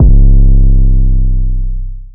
DEEDOTWILL 808 22.wav